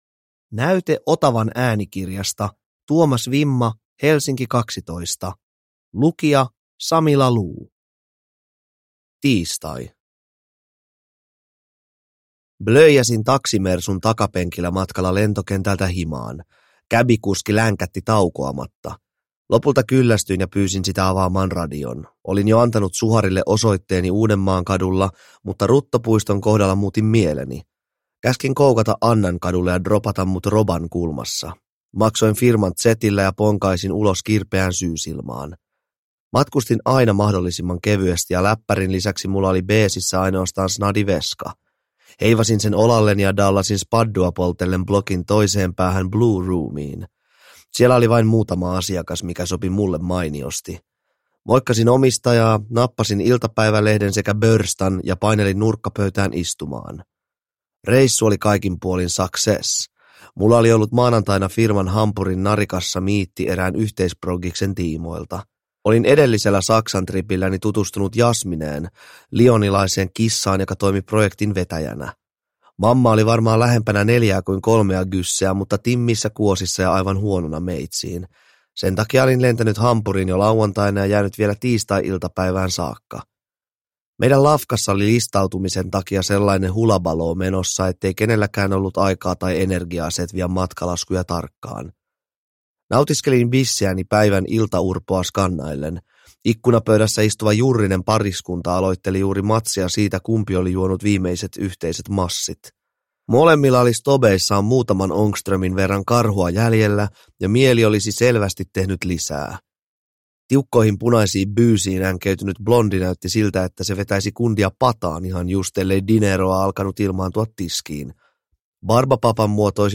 Helsinki 12 – Ljudbok – Laddas ner